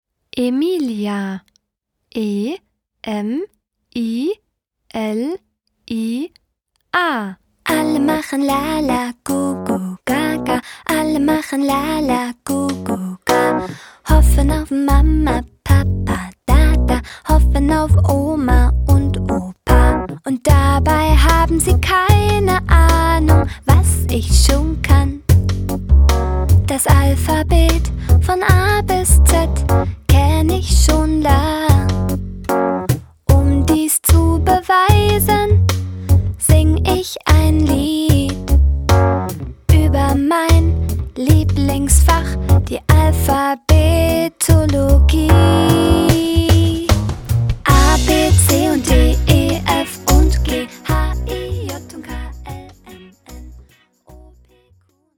Personalisierte Kinderlieder für den ganzen Tag.